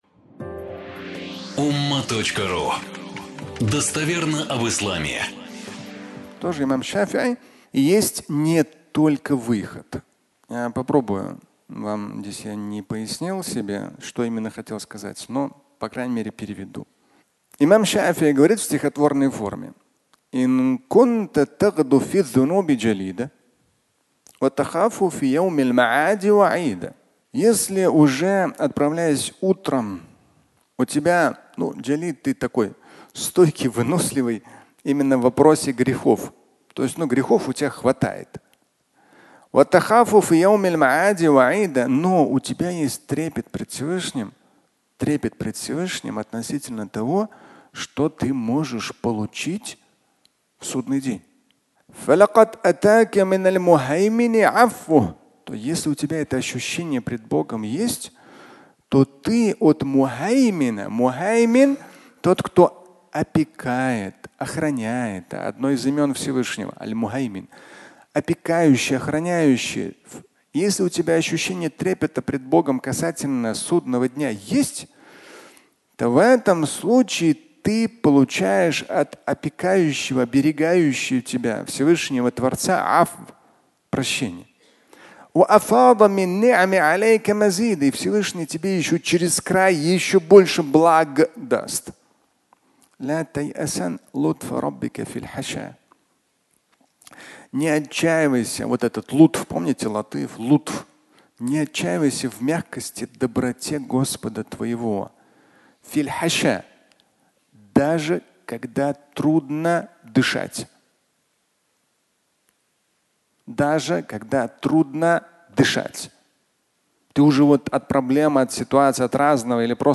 Пятничная проповедь